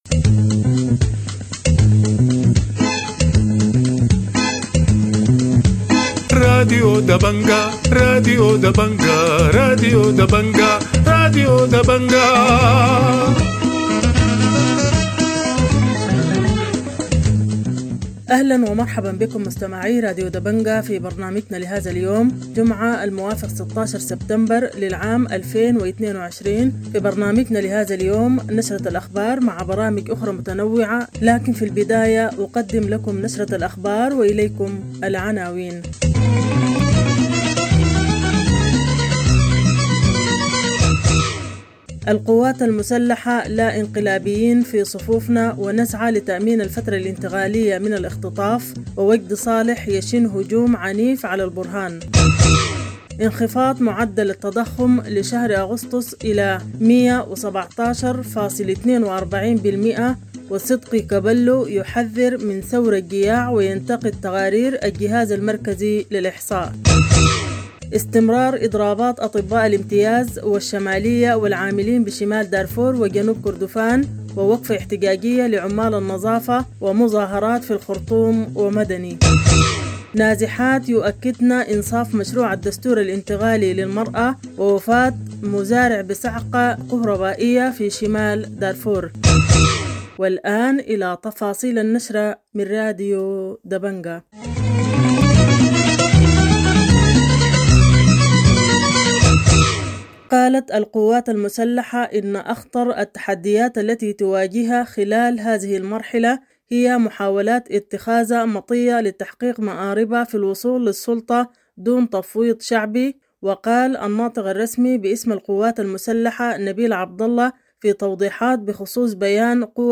Morning news 16 September - Dabanga Radio TV Online